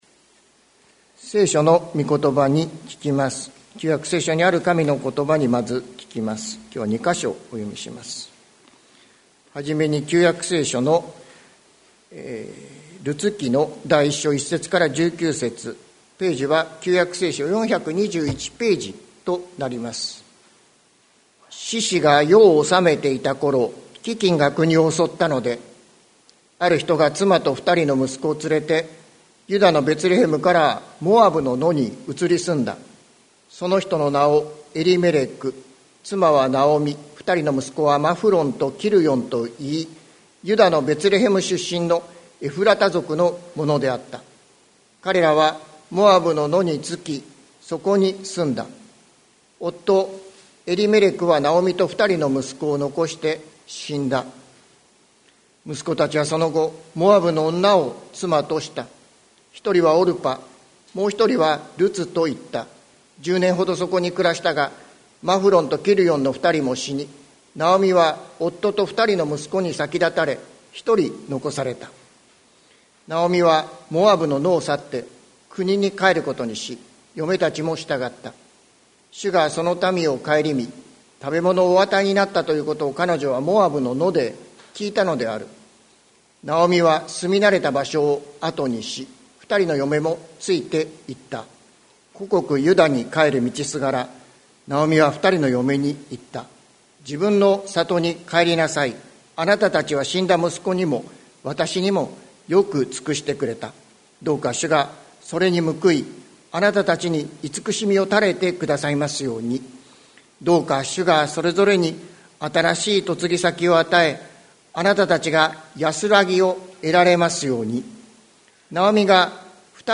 2022年12月11日朝の礼拝「ベツレヘムの奇跡」関キリスト教会
説教アーカイブ。